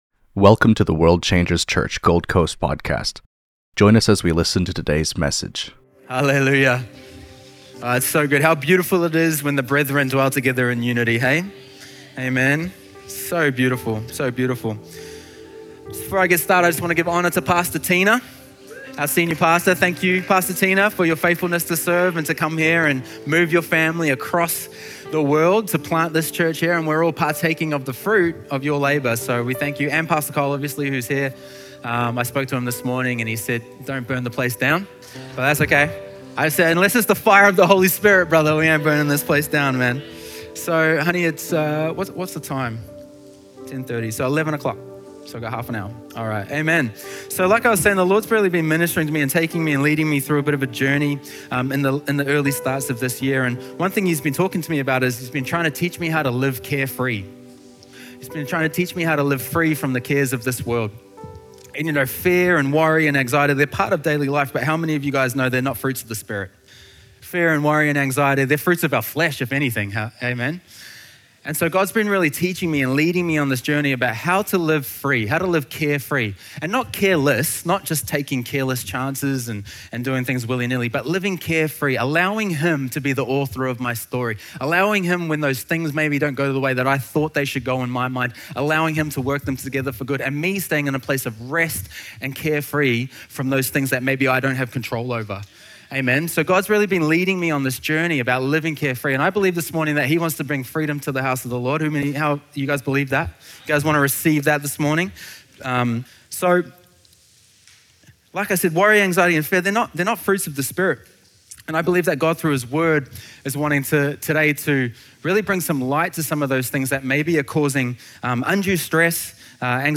The sermon explores how condemnation separates from God, but through Christ, believers are declared worthy, accepted, and empowered.